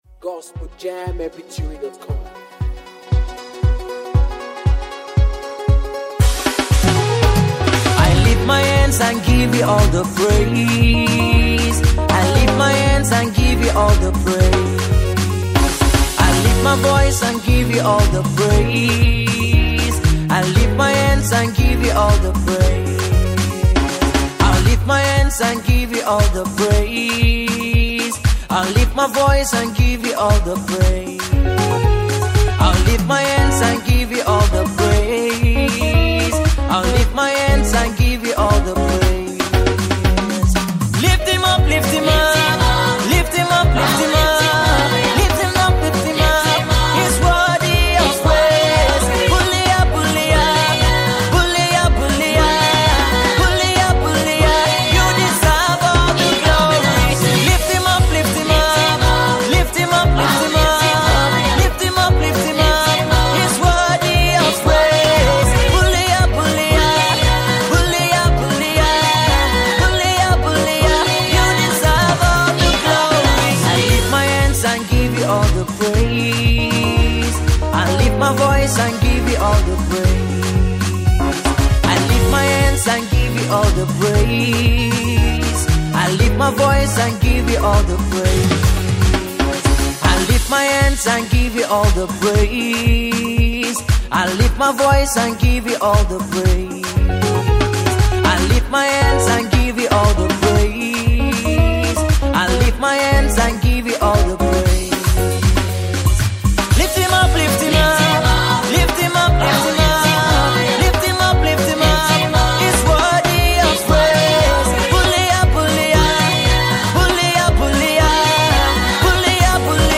African praiseAfro beatmusic